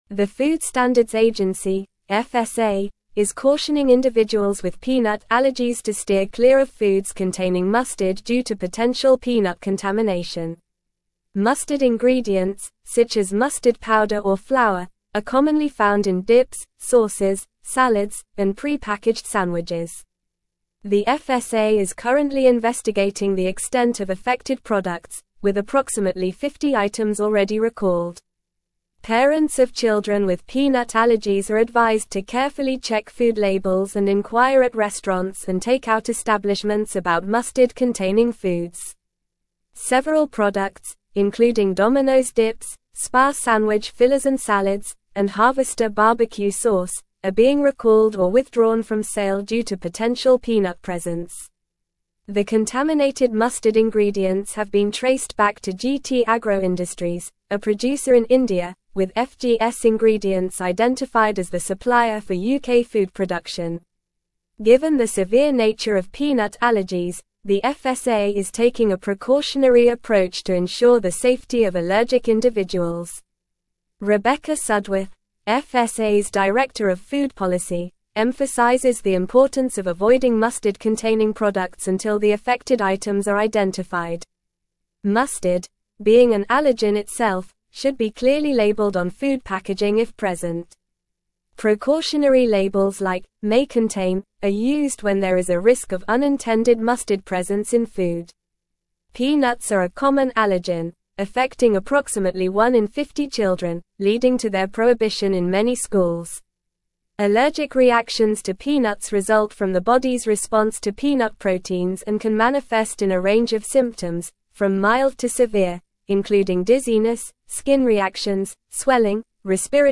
Normal
English-Newsroom-Advanced-NORMAL-Reading-FSA-Warns-of-Peanut-Contamination-in-Mustard-Products.mp3